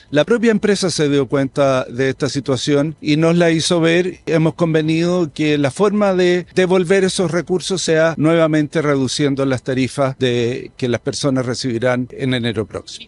El biministro de Energía y Economía, Álvaro García, confirmó en un punto de prensa que la empresa TransELEC realizó un cobro adicional indebido en las tarifas eléctricas por una sobrevaloración de su capital, lo que generó un nuevo error en los montos cobrados a los usuarios.